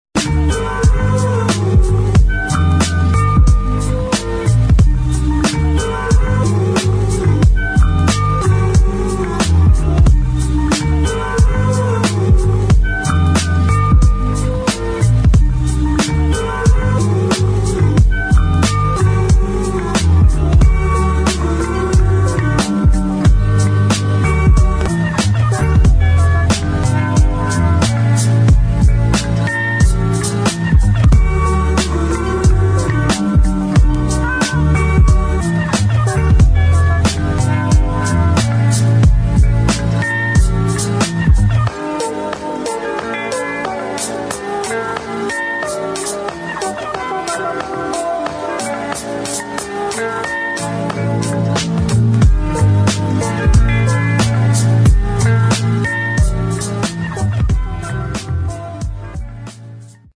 DOWNBEAT / ELECTRONIC